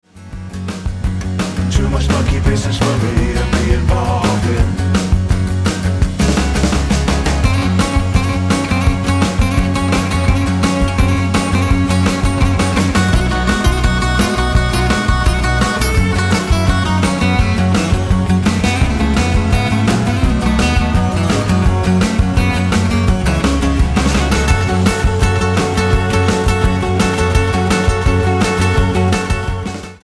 Tonart:F# mit Chor